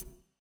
sfx-pm-button-noclick.ogg